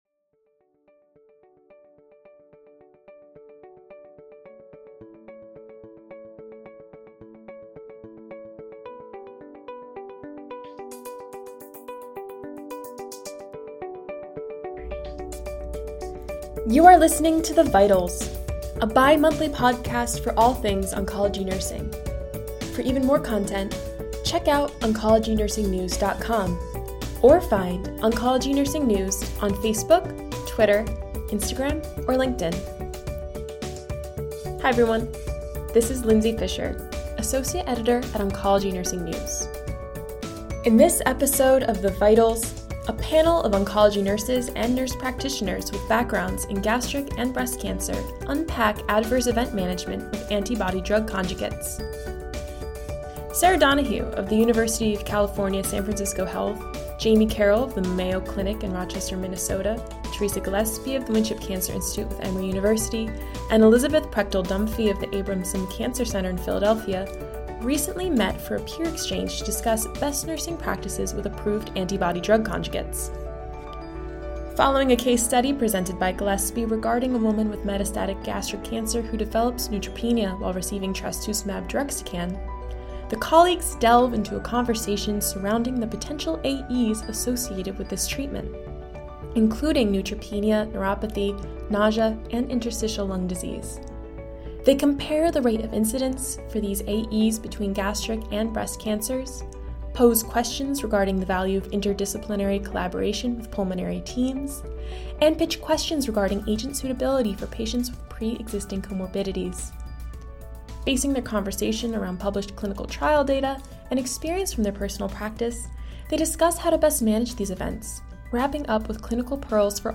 In this episode of The Vitals, a panel of oncology nurses and nurse practitioners with backgrounds in gastric and breast cancer unpack adverse event (AE) management with anti-body drug conjugates (ADCs).